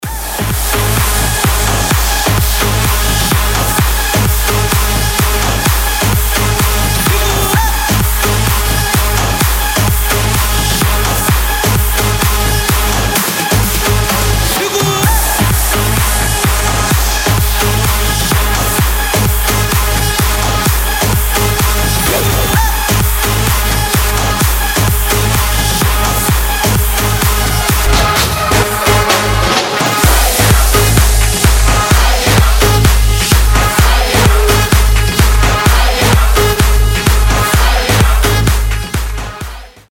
• Качество: 320, Stereo
громкие
заводные
dance
EDM
Стиль: electro house